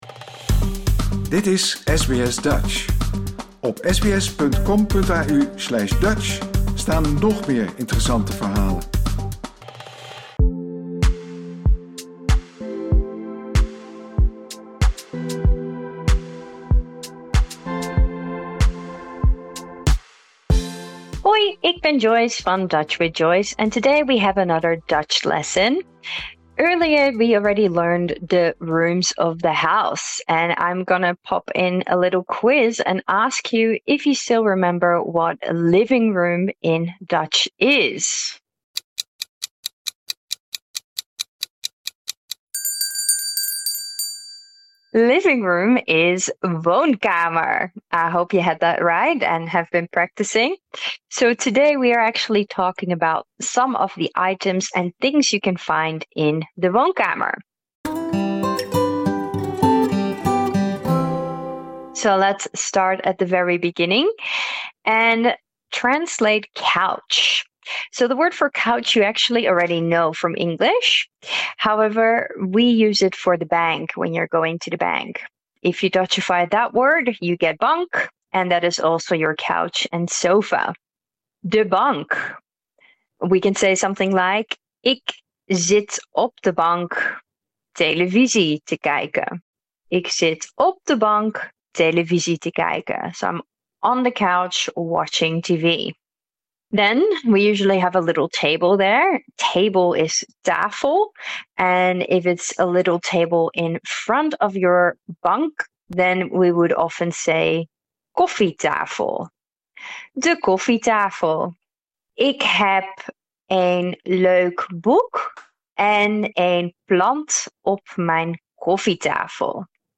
mini-lesson